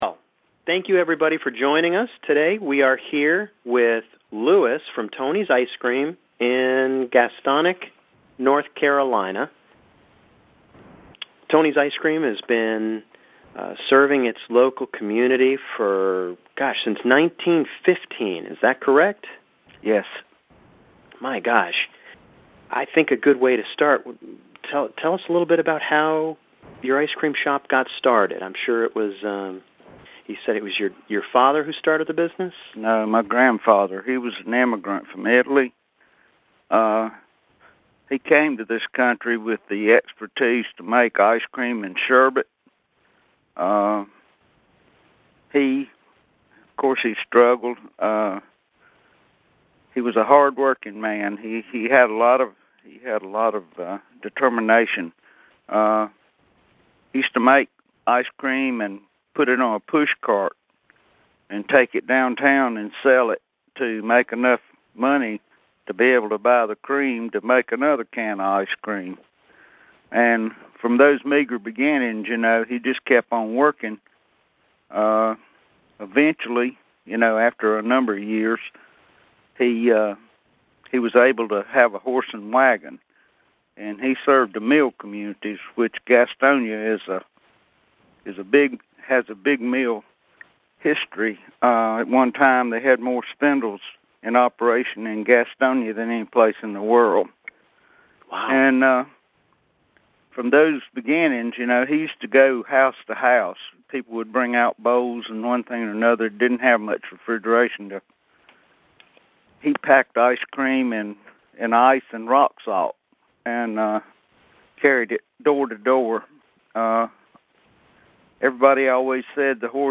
Tony's Ice Cream Interview